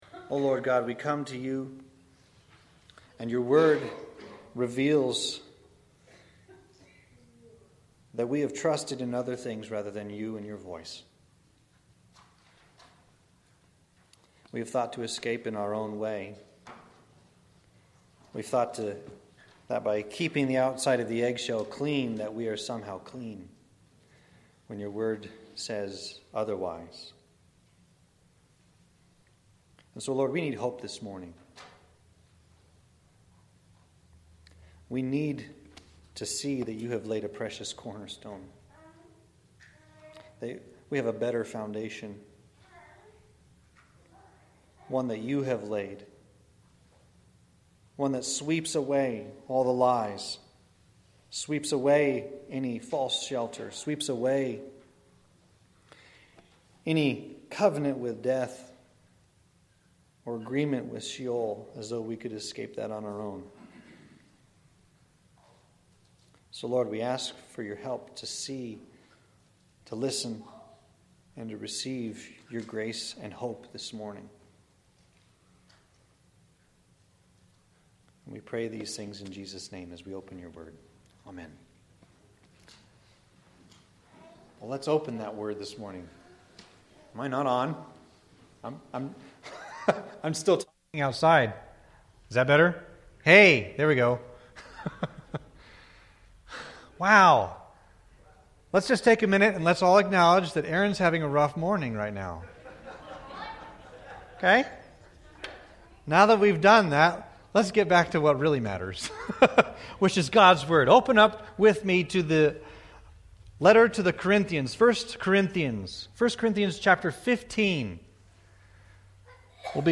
Sermons | York Evangelical Free Church